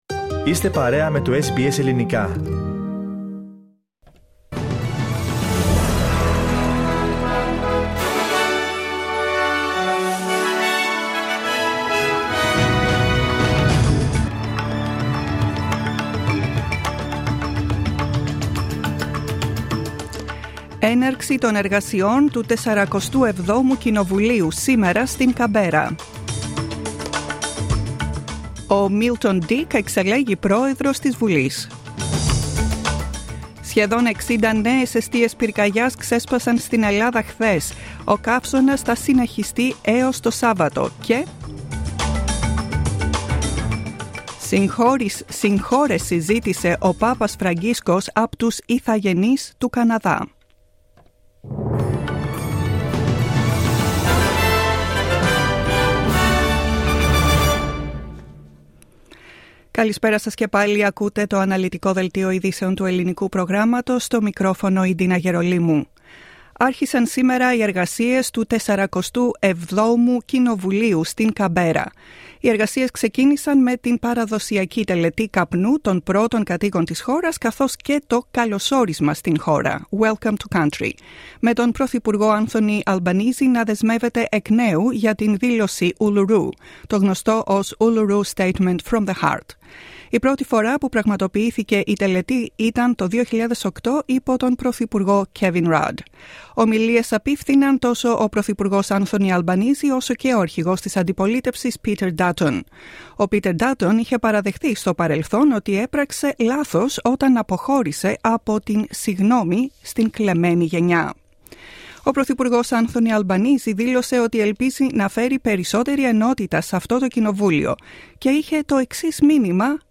News bulletin in Greek 26/07/2022